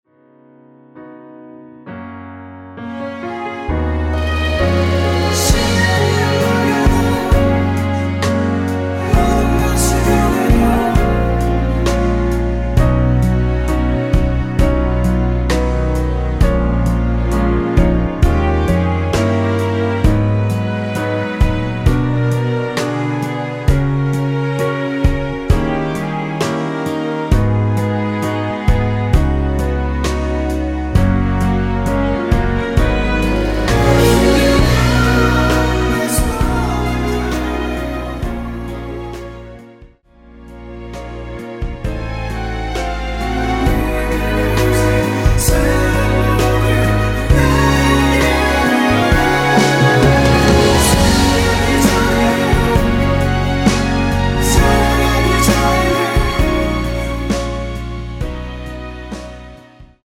원키에서(+1)올린 코러스 포함된 MR 입니다.(미리듣기 참조)
F#
앞부분30초, 뒷부분30초씩 편집해서 올려 드리고 있습니다.
중간에 음이 끈어지고 다시 나오는 이유는